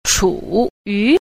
3. 處於 – chǔyú – xử vu (rơi vào)
chu_yu.mp3